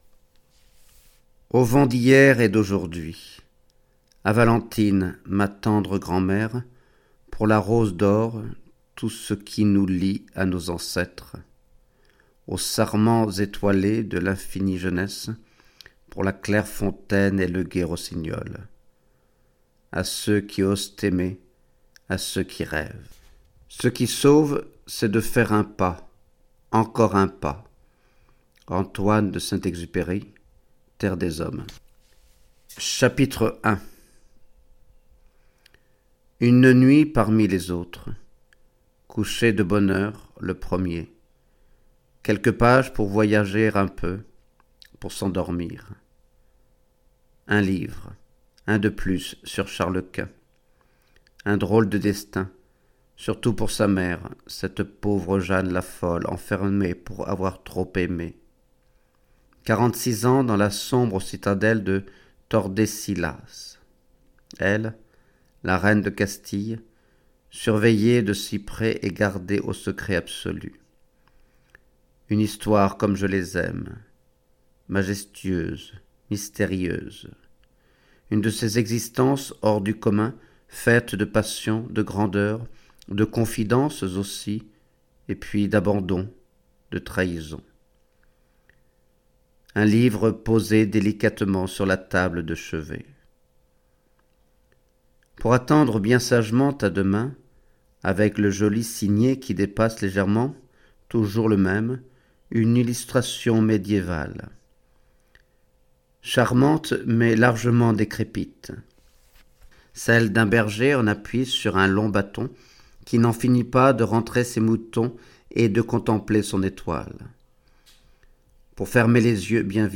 Audiolecture: Dans le silence d'un père